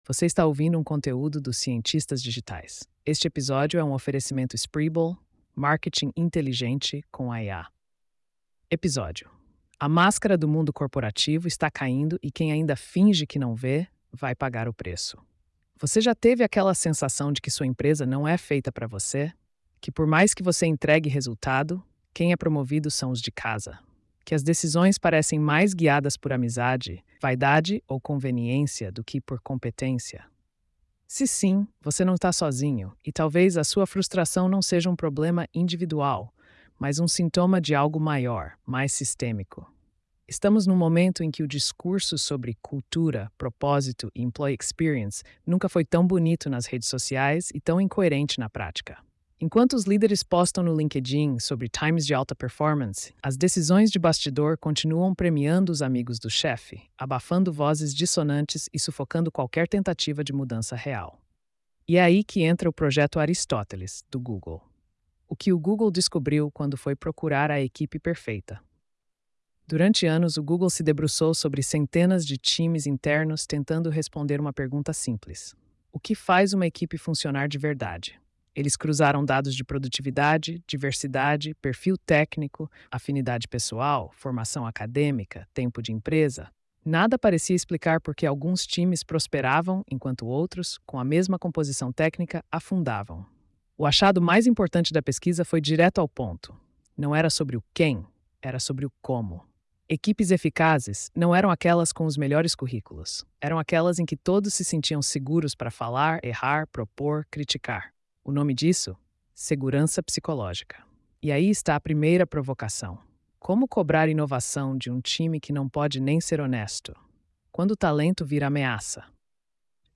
post-4138-tts.mp3